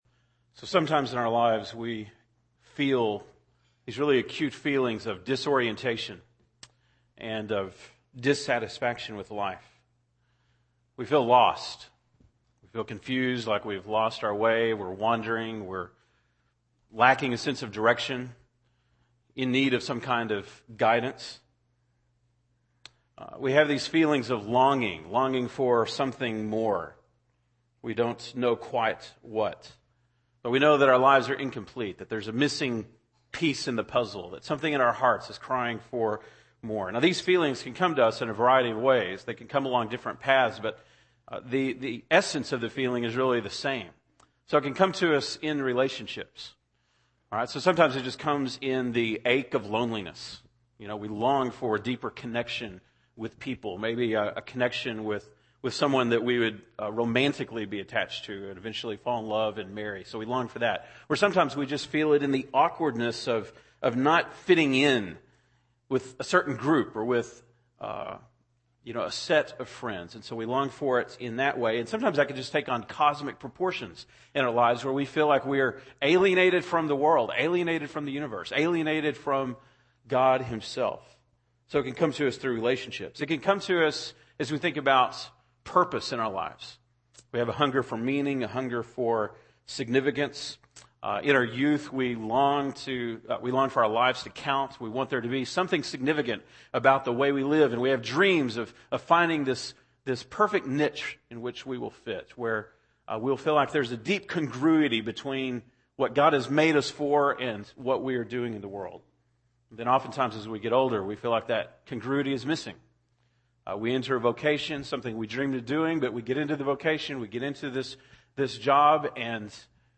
August 29, 2010 (Sunday Morning)